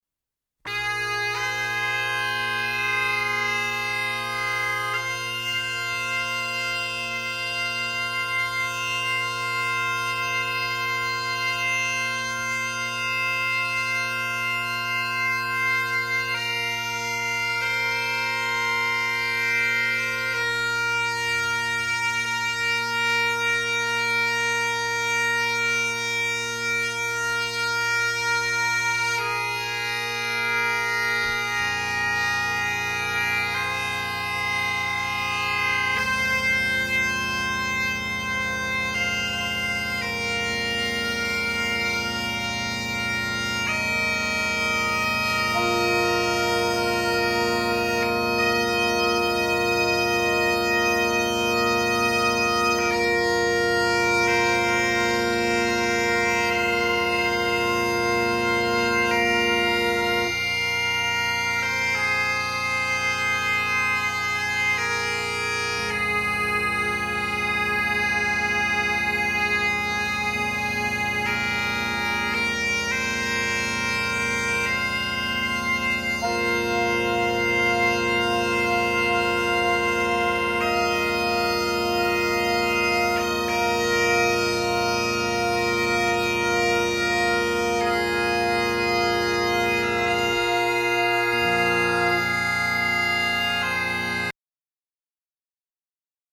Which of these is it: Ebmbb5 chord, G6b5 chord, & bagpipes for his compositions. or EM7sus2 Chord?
& bagpipes for his compositions.